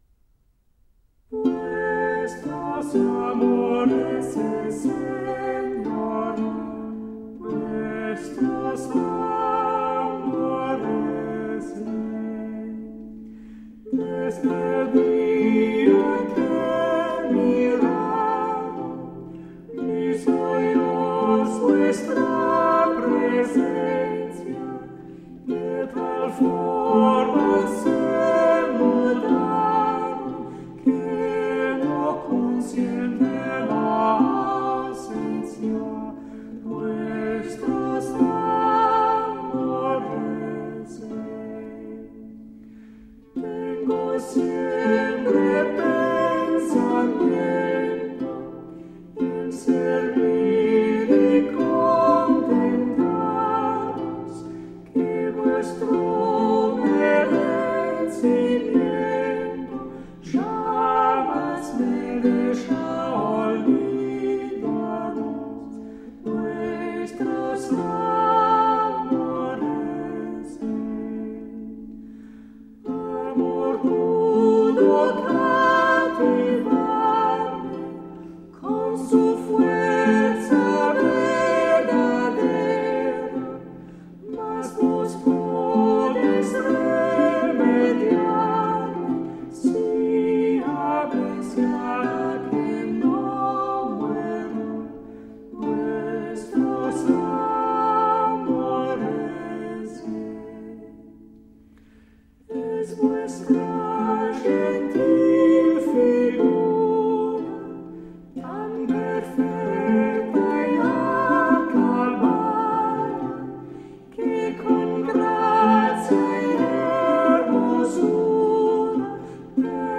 Classical, Renaissance, Classical Singing
Flute, Lute, Viola da Gamba